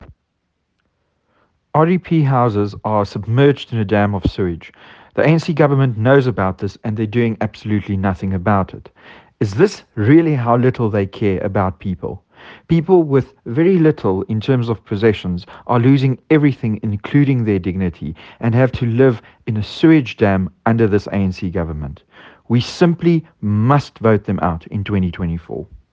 Afrikaans soundbites by George Michalakis MP and